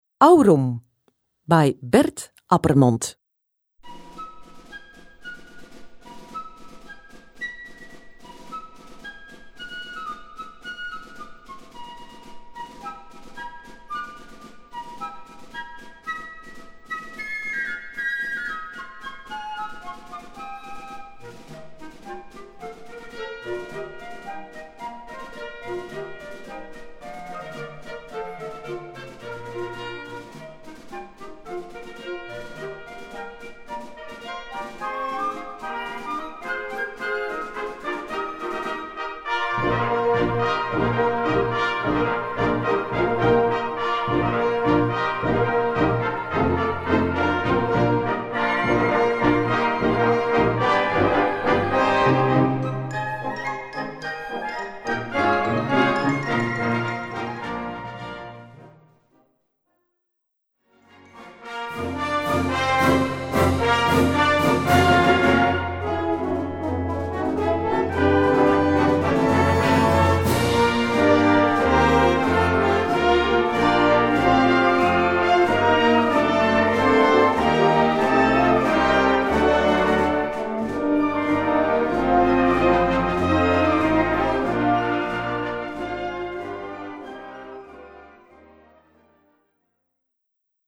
Gattung: Marsch
Besetzung: Blasorchester
Der Marsch beginnt mit einem verspielten, leichten Thema.